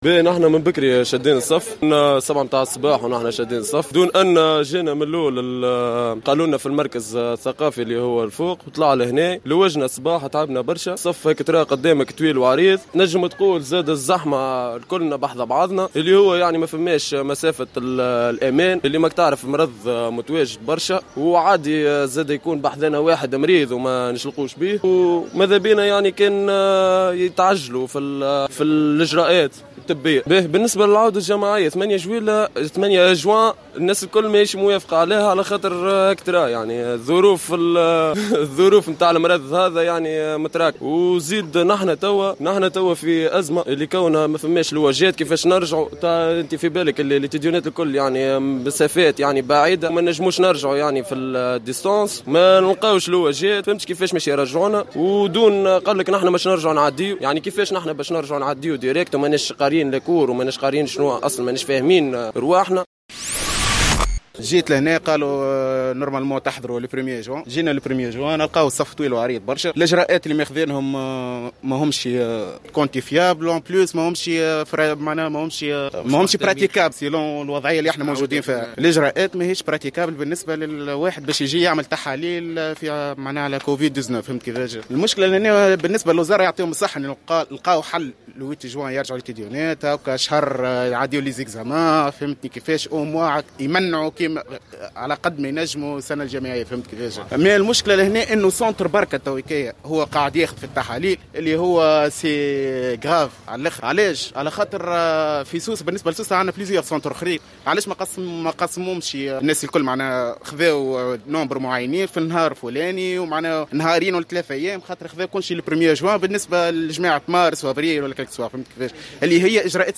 و اعتبر الطلبة الذين استقبلهم المركز الثقافي الجامعي يحيى بن عمر بسوسة في تصريح للجوهرة "اف ام" أن ظروف الخضوع لعملية التقصي لا تراعي معايير السلامة و الوقاية مؤكدين أن الإجراءات المعلن عنها من قبل الوزارتين ليست قابلة للتطبيق واقعيا .